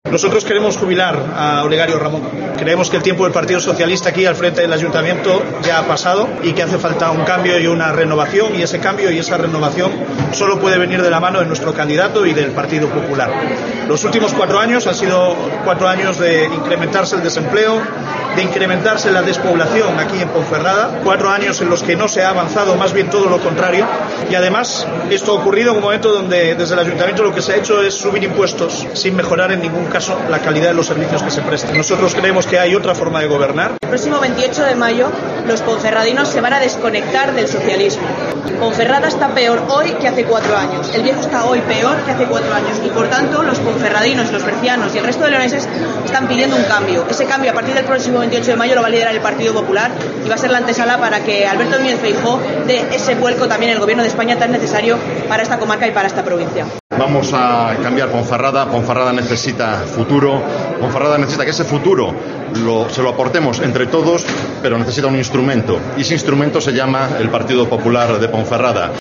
Ha sido en un acto celebrado en el Hotel Ponferrada Plaza y en él que ha estado el vicesecretario general de Organización del Partido Popular, Miguel Tellado